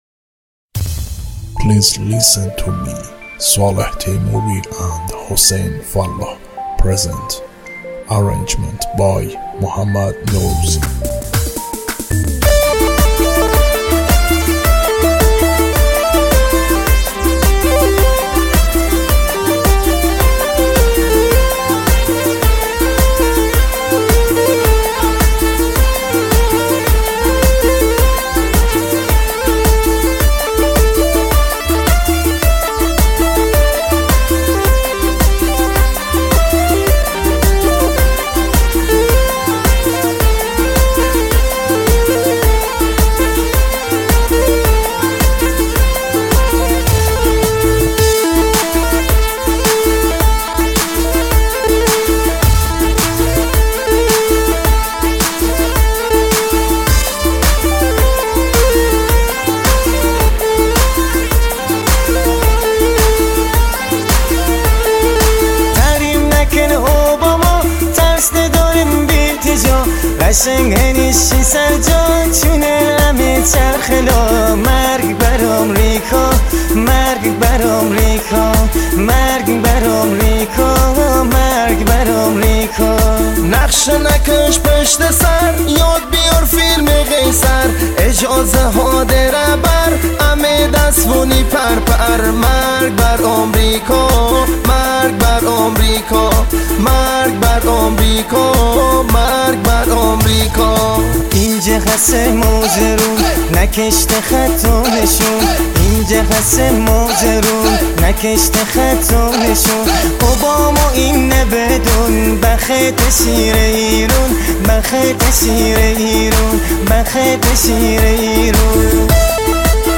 آهنگ مازندرانی
آهنگ شاد